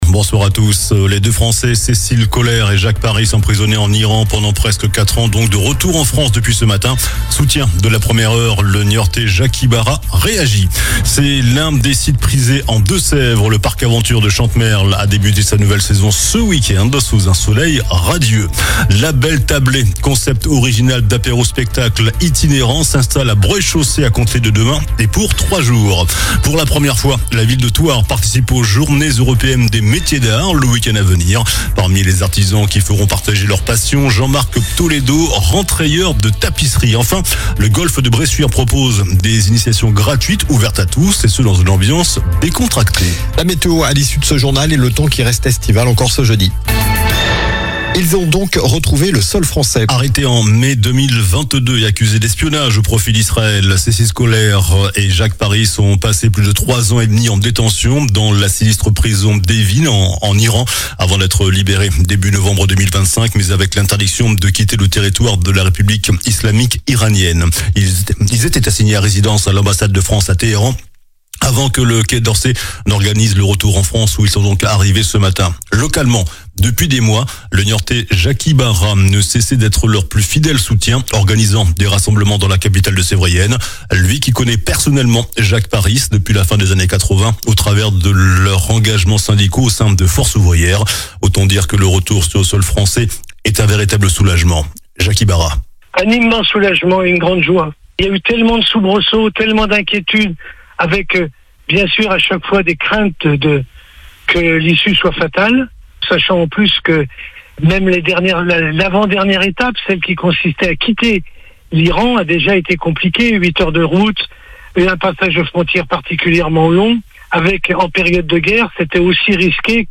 JOURNAL DU MERCREDI 08 AVRIL ( SOIR )